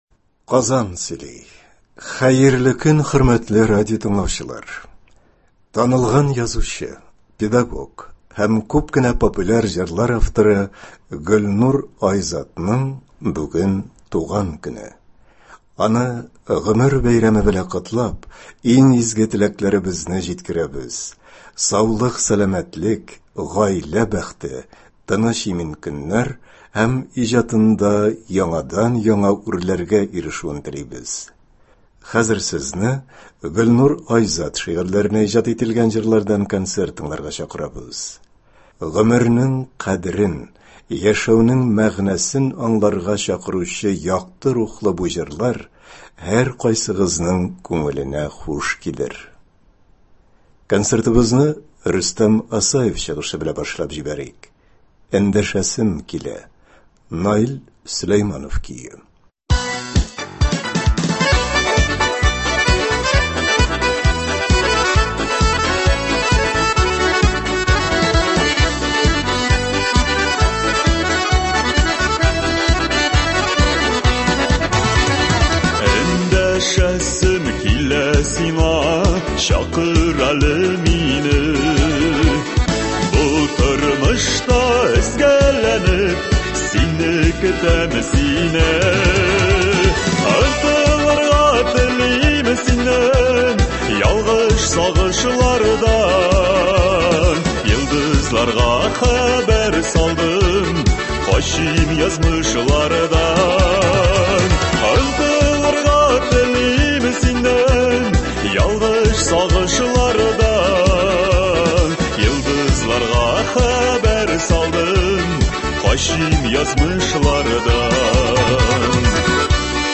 Кичке концерт. Муса Җәлил шигырьләренә лирик җырлар.